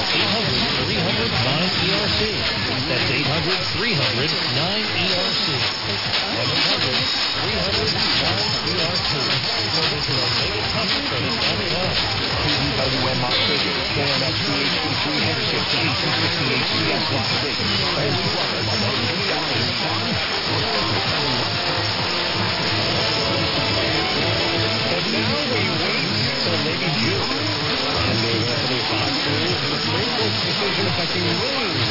KDWN did go full-power just before 0750z/11:50pm PST, did do a proper send-off w/ a song about Nevada, National Anthem, and even some SSTV & CW... and then they returned to normal programming @ 0810z/00:10am PST, reducing power back to whatever they use at night a few minutes later... And after 1100z/03:00am PST THEY ARE STILL ON THE AIR ON 720 AM!!!